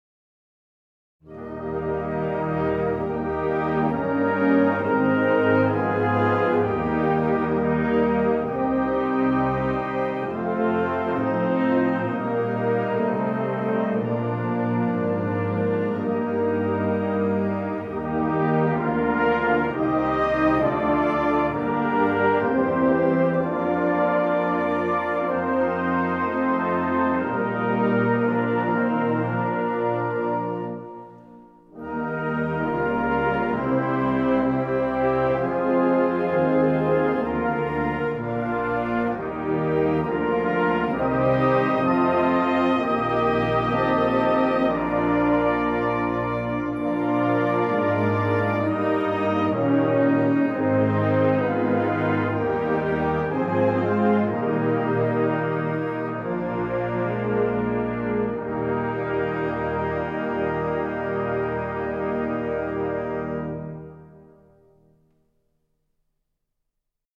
Gattung: Choralsammlung
Besetzung: Blasorchester